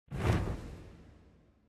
Minecraft Version Minecraft Version snapshot Latest Release | Latest Snapshot snapshot / assets / minecraft / sounds / mob / phantom / flap2.ogg Compare With Compare With Latest Release | Latest Snapshot
flap2.ogg